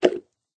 plop_hard.ogg